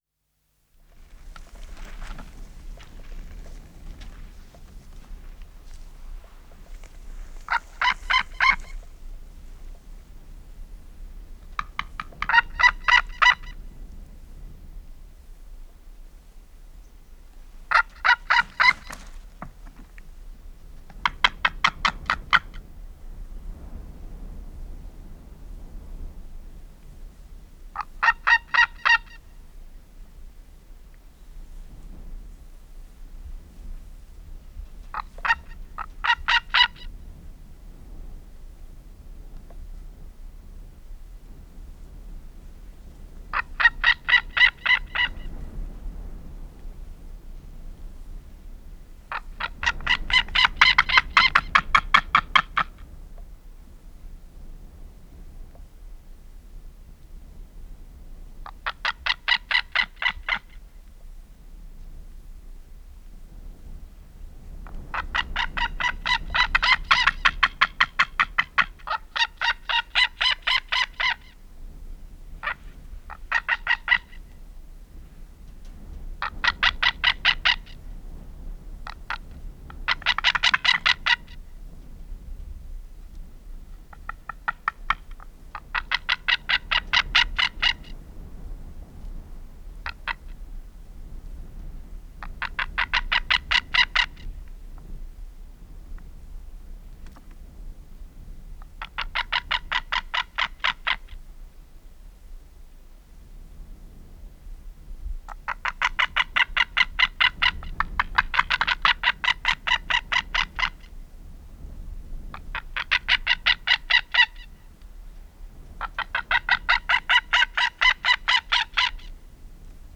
Seeadler Ruf
Ein hohes, bellendes „kli-kli-kli“ – überraschend unspektakulär für seine Größe.
Der Seeadler Ruf klingt erstaunlich hell und ist ein wichtiges Kommunikationsmittel innerhalb des Reviers.
Seeadler-Ruf-Voegel-in-Europa.wav